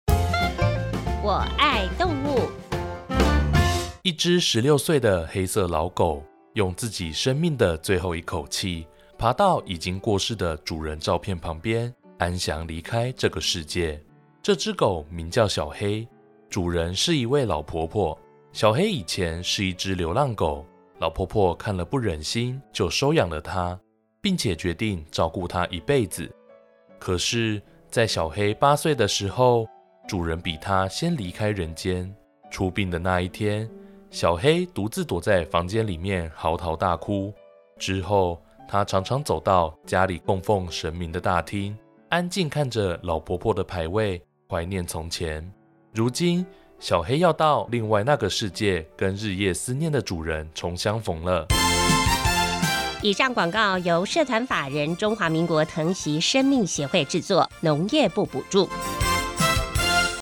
「我愛動物」系列廣播廣告文稿～第 10 集 ～片頭 主講人： 一隻 16 歲的黑色老狗，用自己生命的最後一口氣，爬到已經過世的主人照片旁邊，安詳離開這個世界。